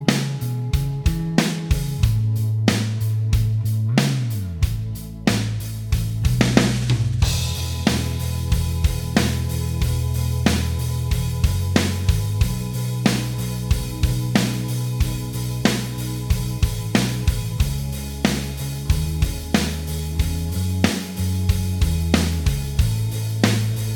Minus Acoustic Guitar Pop (2010s) 3:45 Buy £1.50